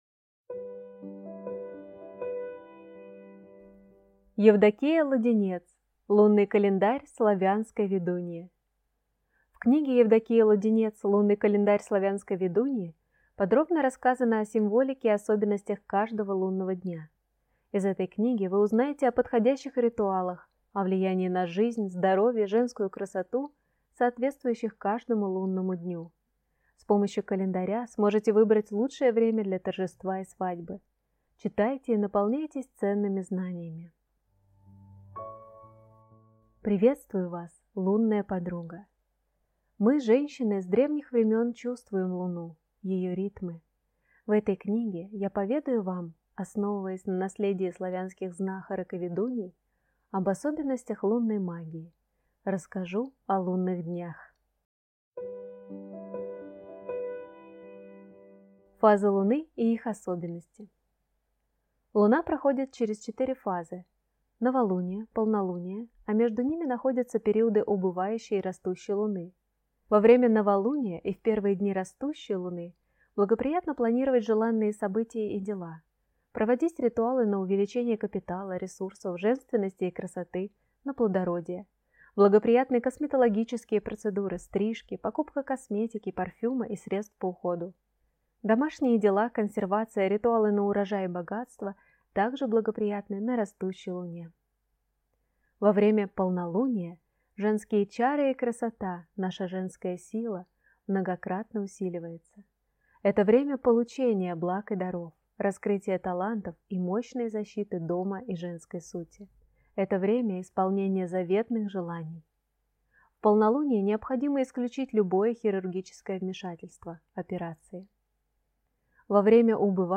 Аудиокнига Лунный календарь славянской ведуньи | Библиотека аудиокниг